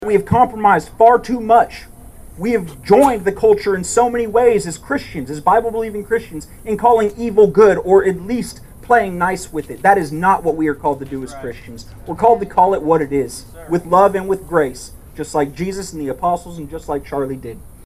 An estimated 300 people gathered outside of Bartlesville City Hall Tuesday night to remember the life and legacy of Charlie Kirk.
The vigil included hymns, prayer and messages from evangelical leaders from across the area.